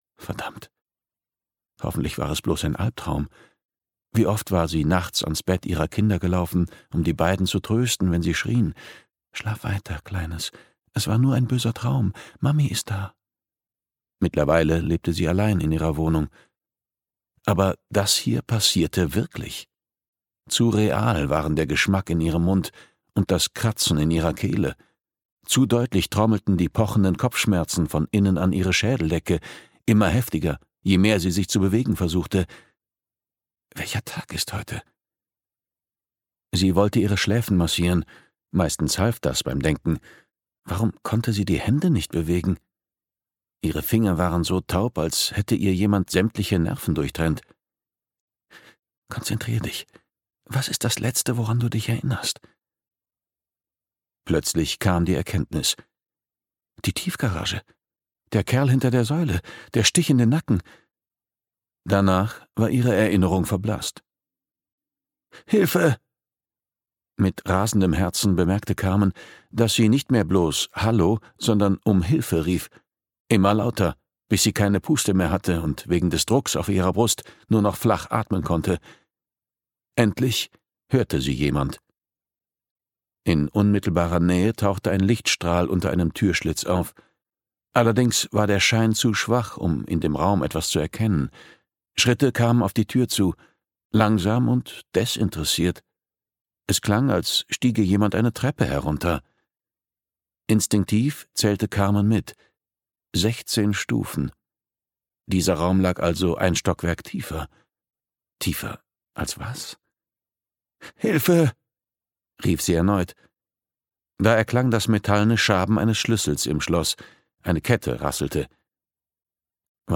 Todesfrist (DE) audiokniha
Ukázka z knihy